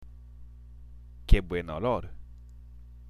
（ケ　ブエン　オロール）